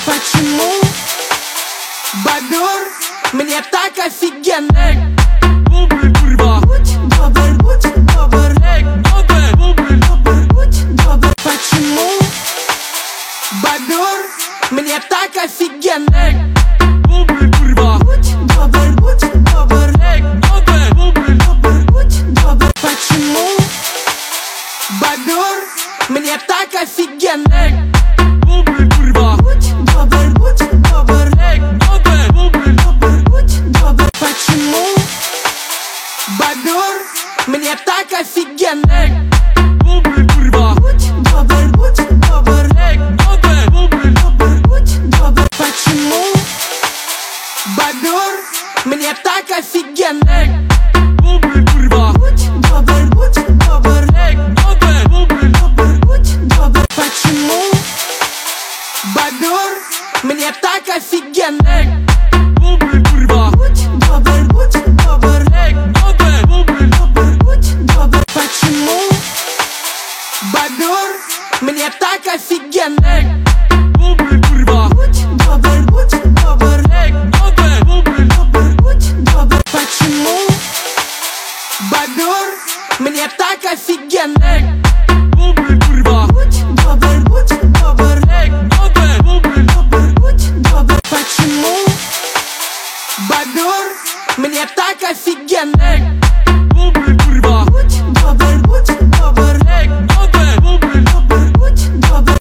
(тикток ремикс)